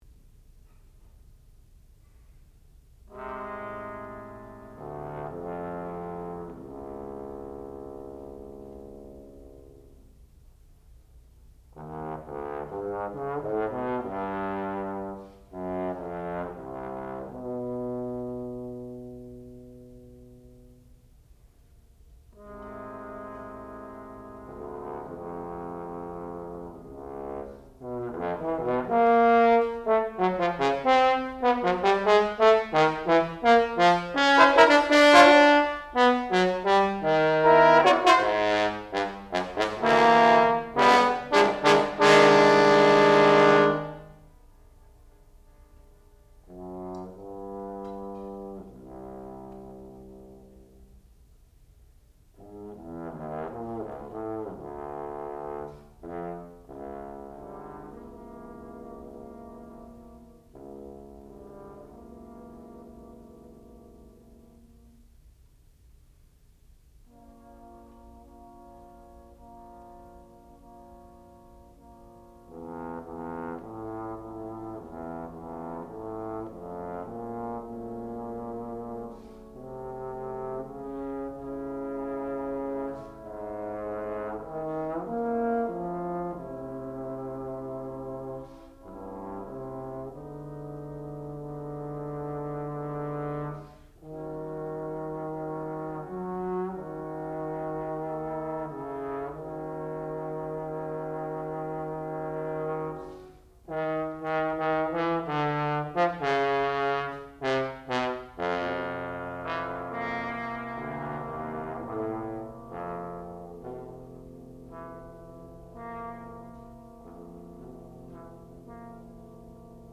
sound recording-musical
classical music
trombone
Master Recital
bass trombone